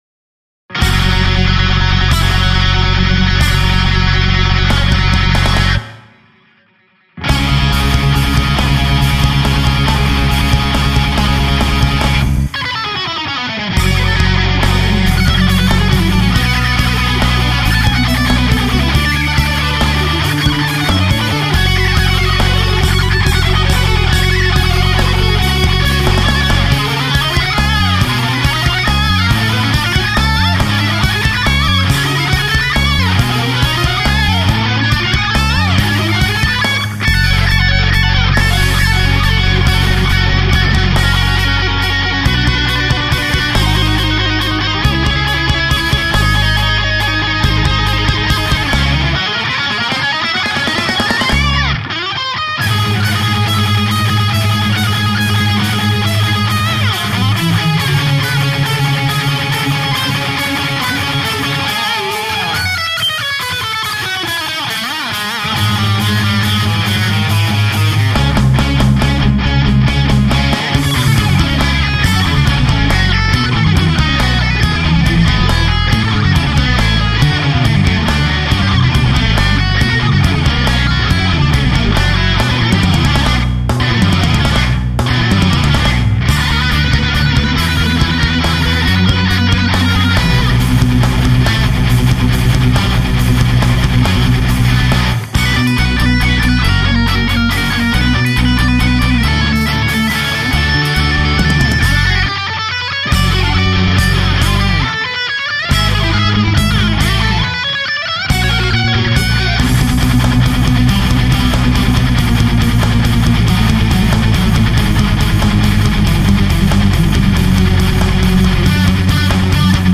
Je upssoie ce sujet juste pour présenter une reprise d'un morceau classique que j'ai faite avec un pote :
il y a des blèmes de niveau son sur l'enregistrement